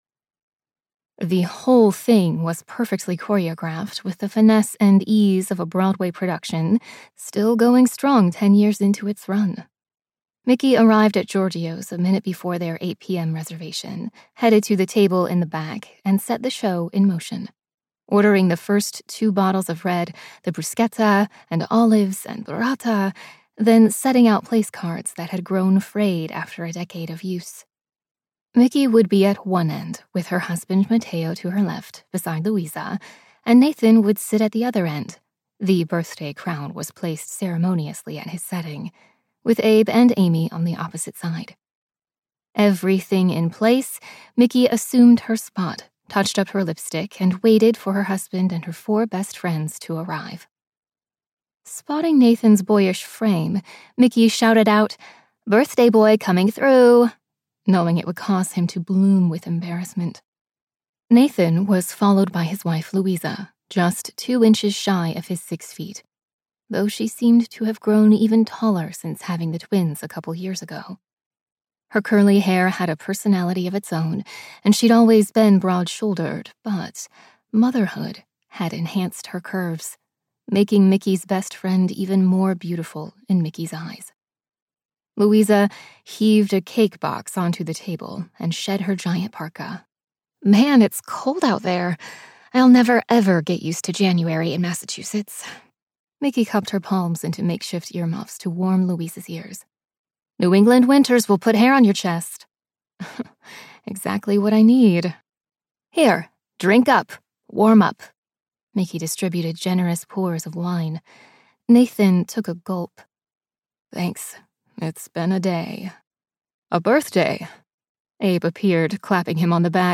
Reservations for Six - Vibrance Press Audiobooks - Vibrance Press Audiobooks